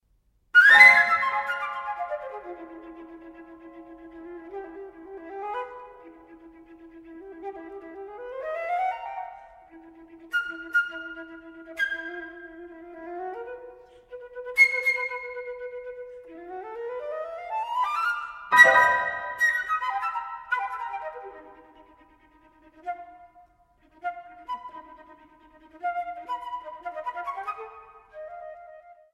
Flute
Clarinet
Piano.